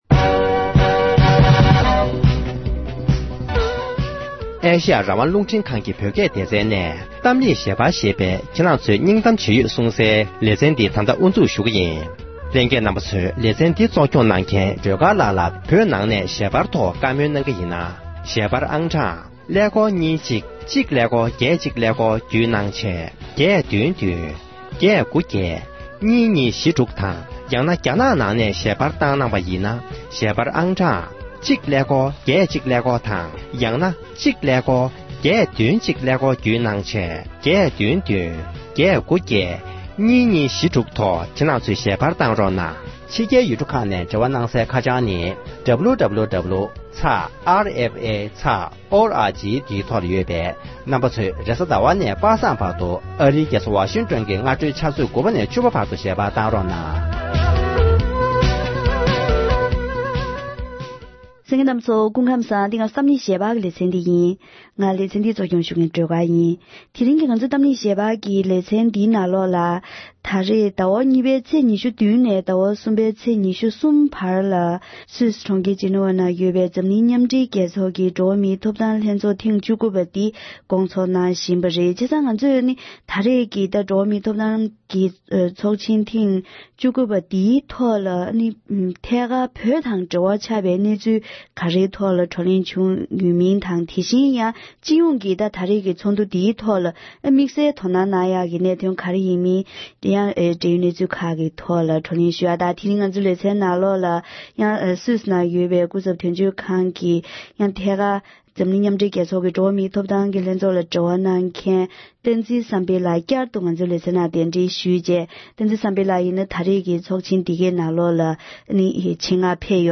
འབྲེལ་ཡོད་མི་སྣ་དང་བགྲོ་གླེང་ཞུས་པར་གསན་རོགས་ཞུ༎